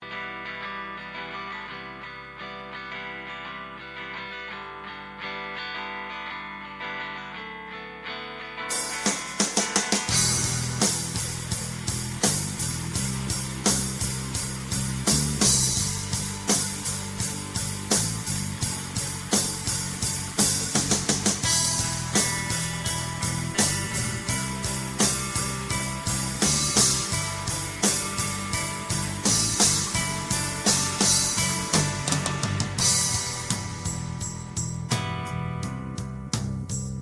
We are live 8-12 every Sunday and Wednesday from the Morrison Holiday Bar